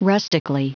Prononciation du mot rustically en anglais (fichier audio)
Prononciation du mot : rustically